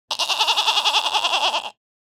Goat-bleating-excited-2.mp3